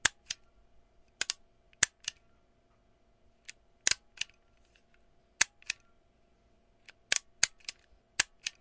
音效 " 订书机
描述：使用Tascam DR07 MK II录制
Tag: 订书钉 订书机 链路